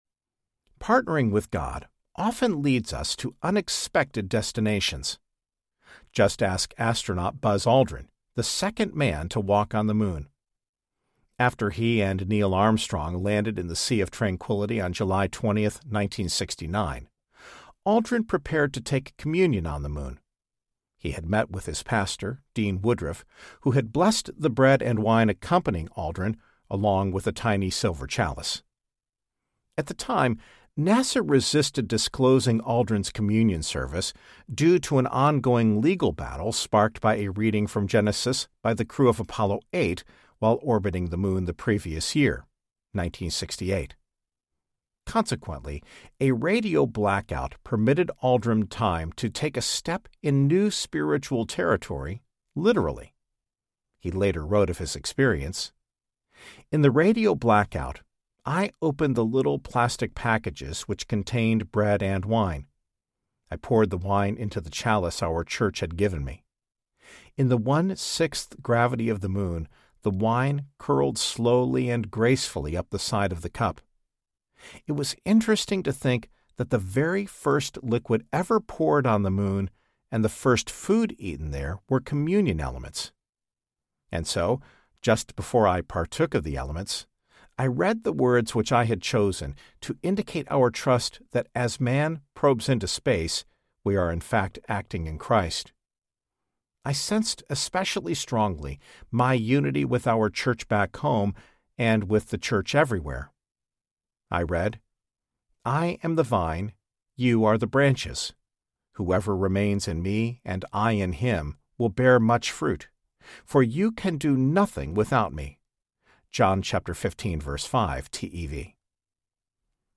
Your Prophetic Life Map Audiobook
Narrator
5.7 Hrs. – Unabridged